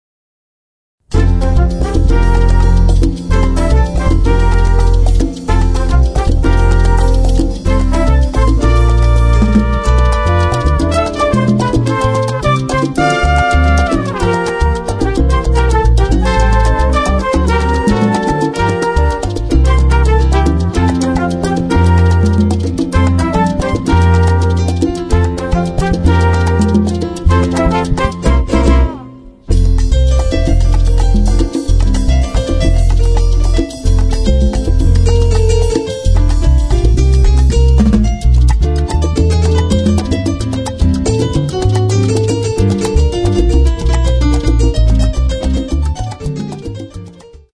guitarra
Bajo, Contrabajo